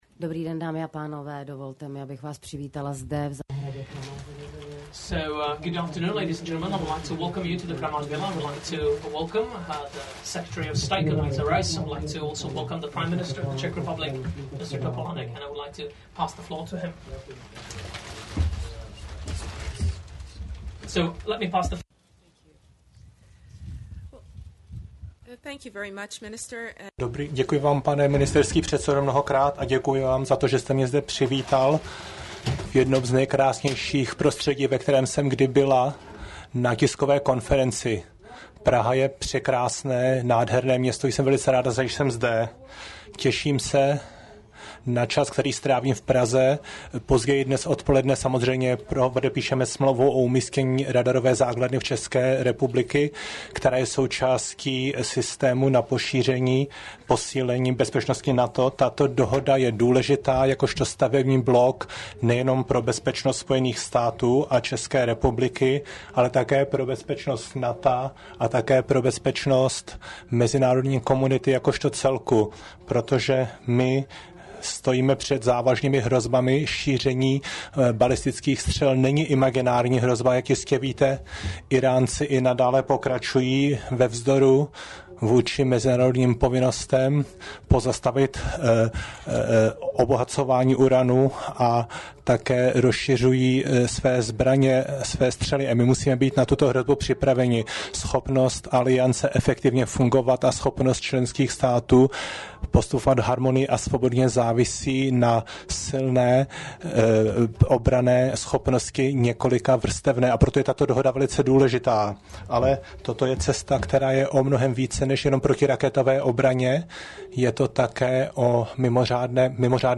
Tisková konference premiéra M. Topolánka a ministryně zahraničí USA C. Rice 8. 7. 2008